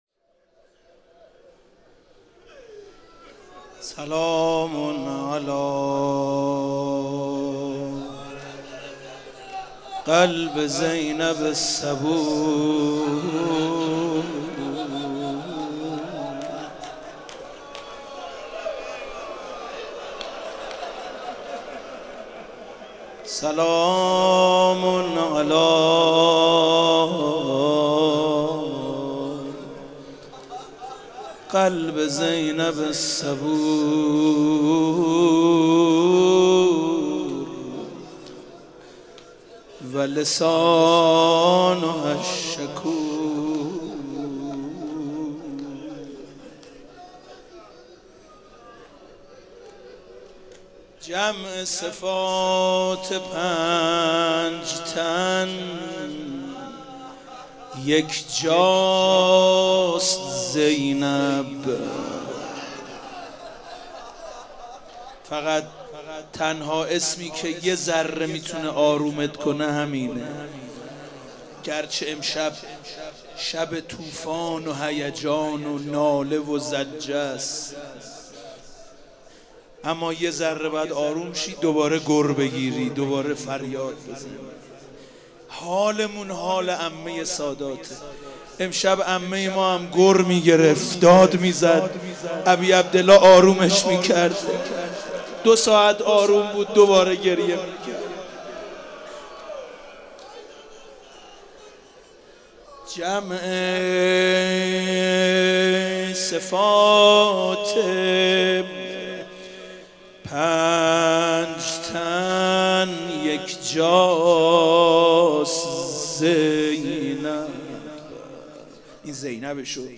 شب عاشورا محرم97 - مسجد امیر - روضه